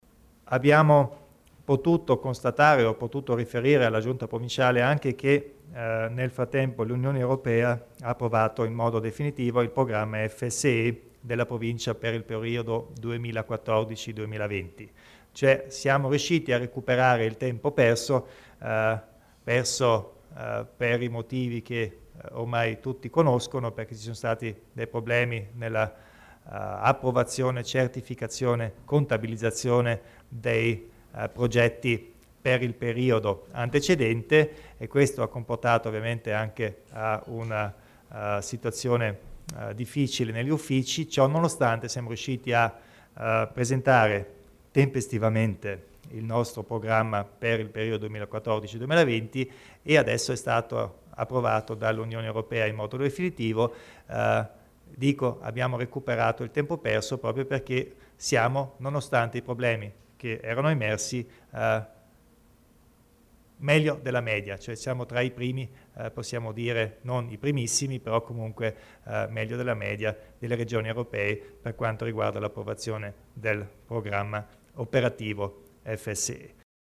Il Presidente Kompatscher illustra nel novità per il FSE